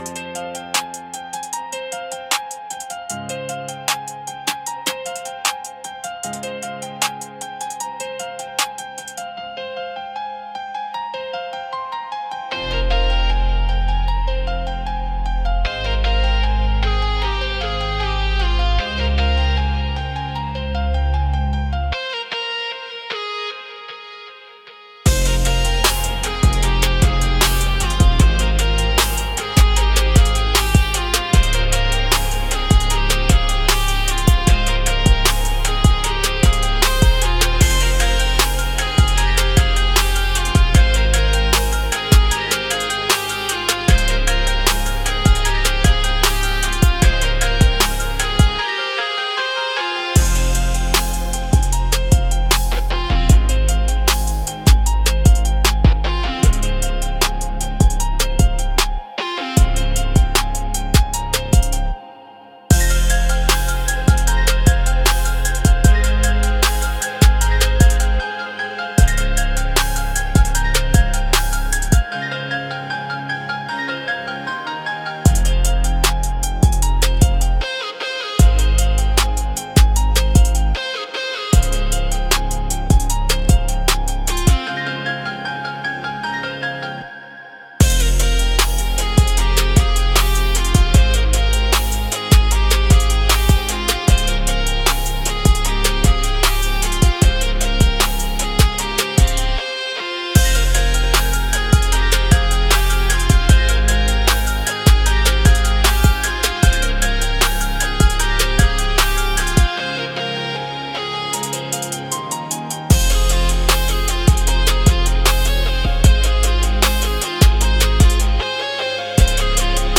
128 BPM
Trap